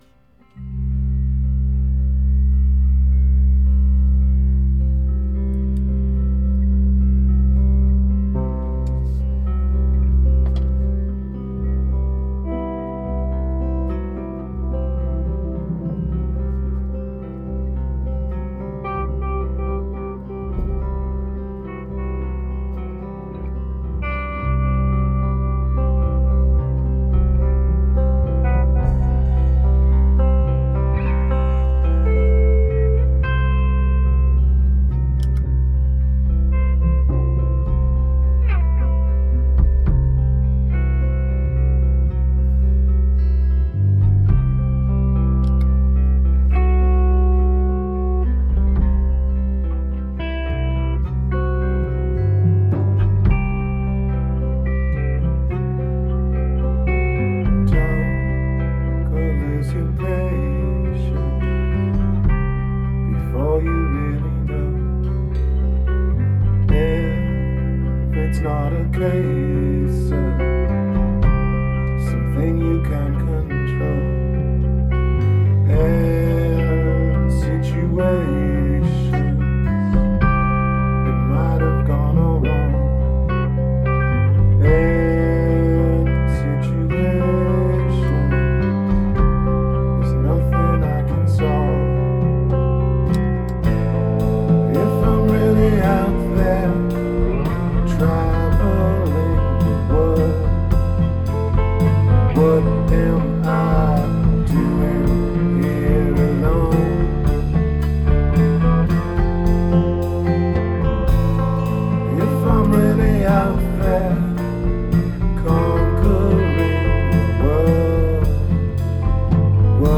Rehearsals 30.7.2013